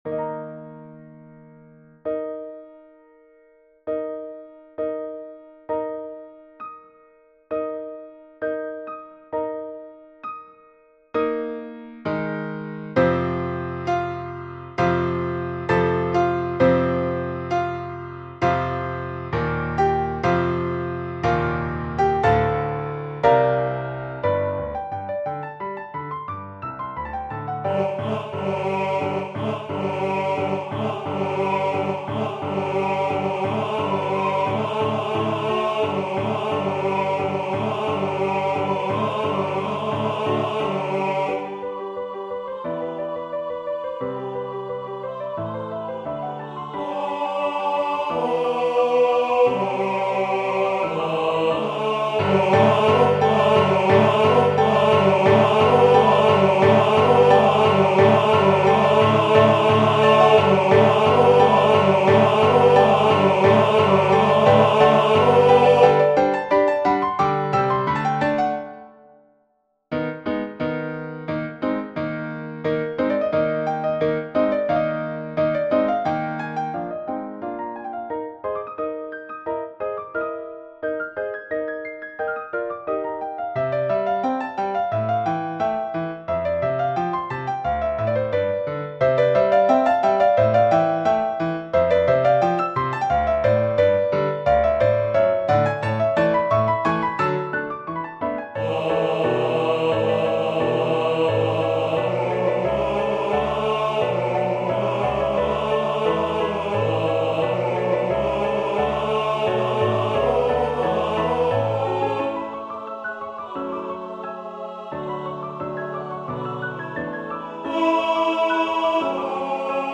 FantasiaCC_Divin_Enfant_Tenor.mp3